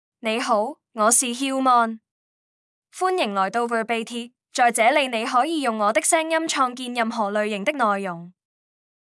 HiuMaanFemale Chinese AI voice
HiuMaan is a female AI voice for Chinese (Cantonese, Traditional).
Voice sample
Listen to HiuMaan's female Chinese voice.
Female
HiuMaan delivers clear pronunciation with authentic Cantonese, Traditional Chinese intonation, making your content sound professionally produced.